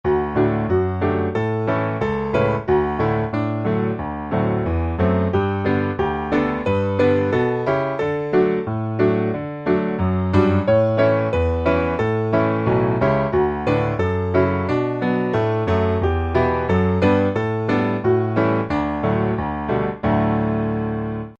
Piano Hymns
D Majeur